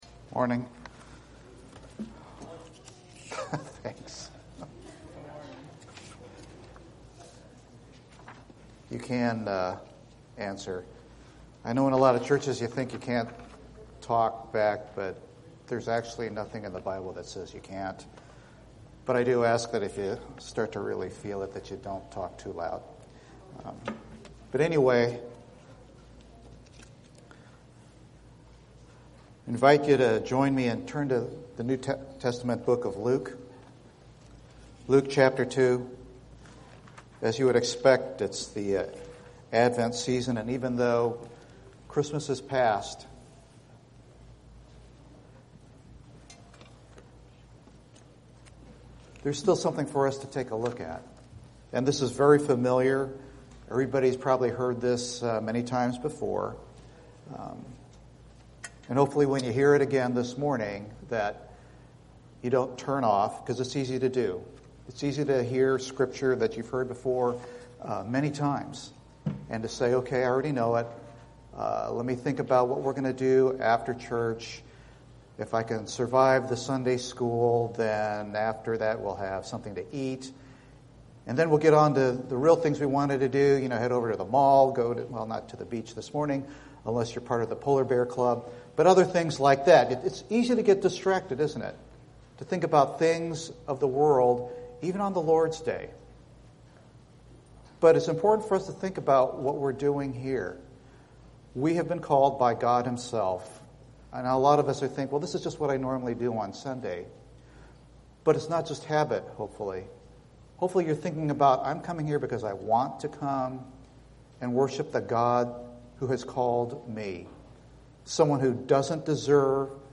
Bible Text: Luke 2:21-35 | Preacher